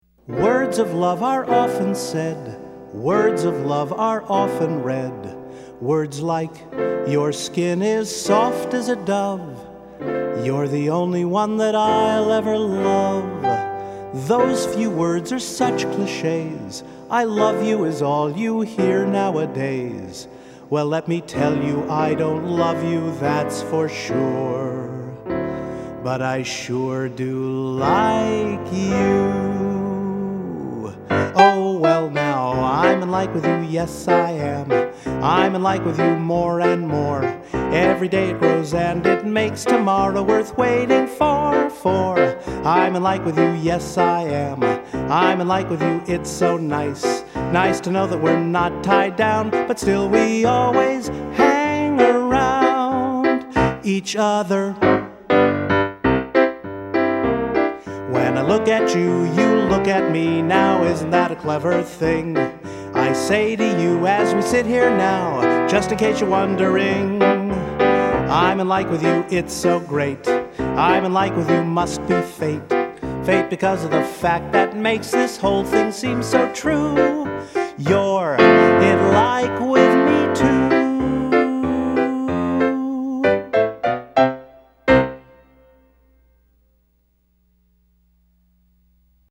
It’s a silly song, but I think it showed at least a little talent and actually has my sense of humor, even at that age.